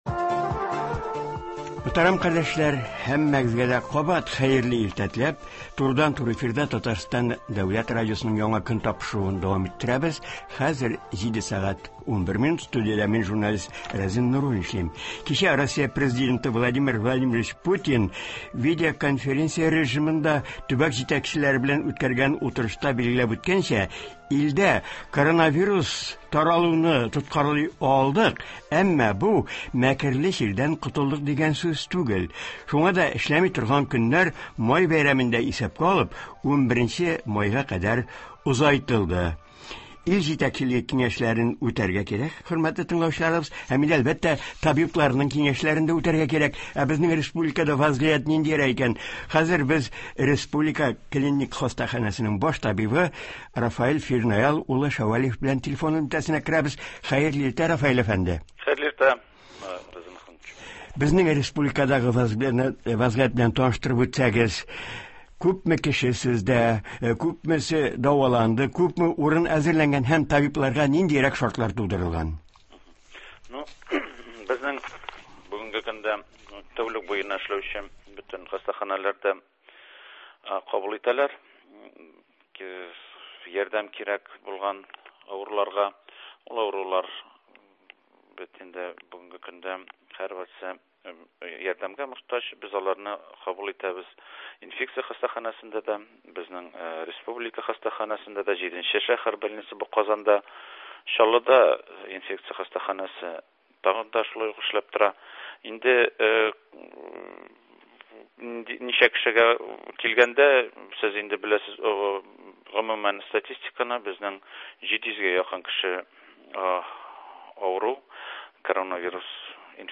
тыңлаучылар сорауларына җавап бирәчәк.
Татарстан авыл хуҗалыгы һәм азык-төлек министры урынбасары Ришат Хәбипов белән әңгәмә шул хакта булачак.